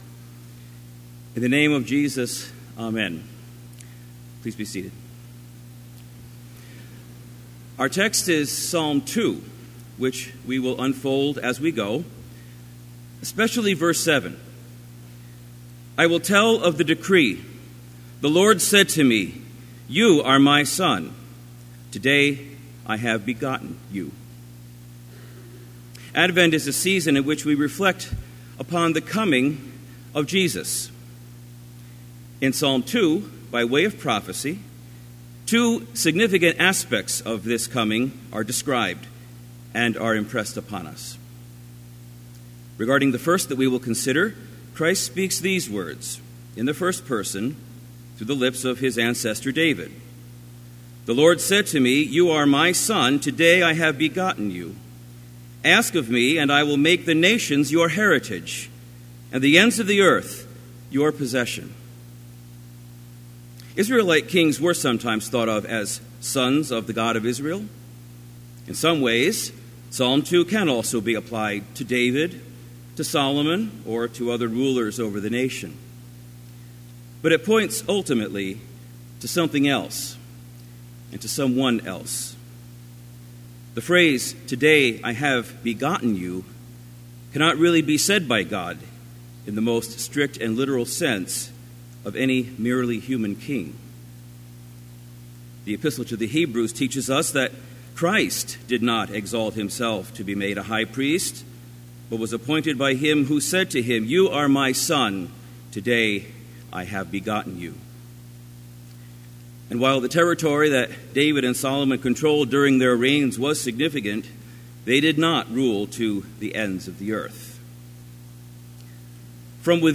Complete service audio for Chapel - December 6, 2017